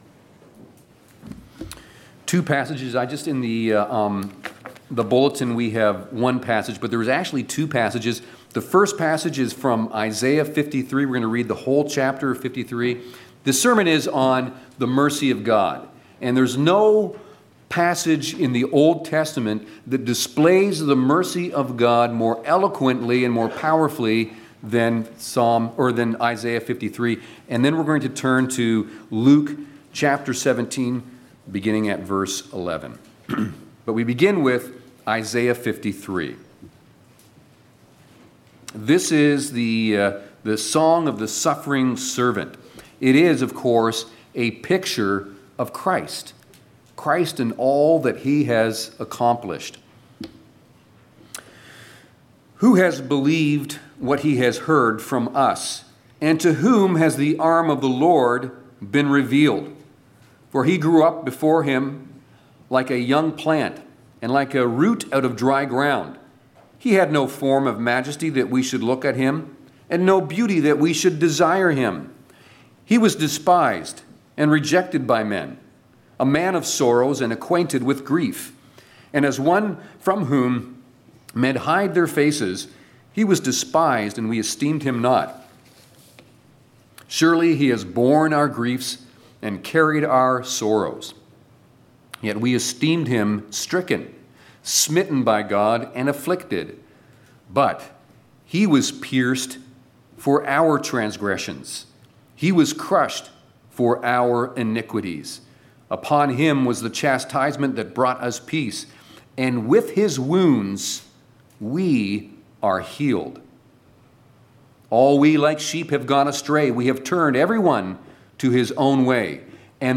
4.-Service-of-Gods-Word-Nov-1-PM-The-Mercy-of-God.mp3